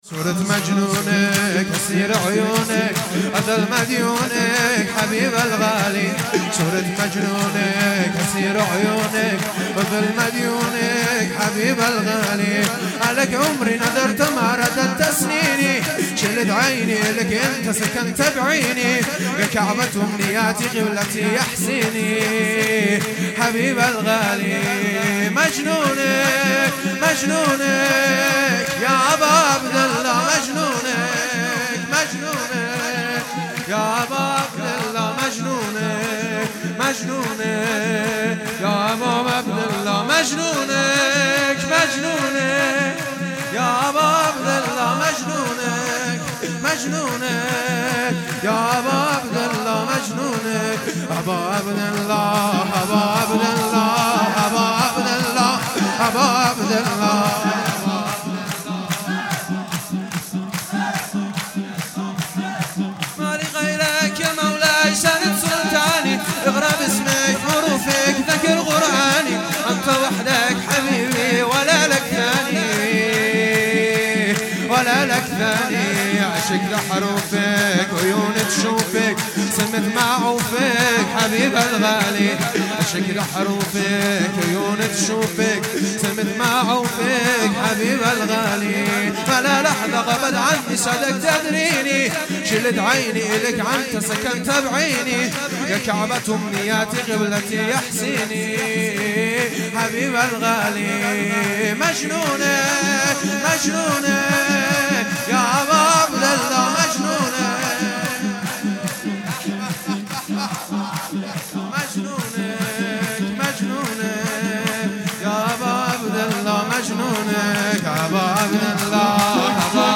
جشن میلاد سرداران کربلا_سال١٣٩٨